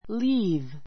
líːv